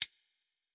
Fantasy Interface Sounds
UI Tight 10.wav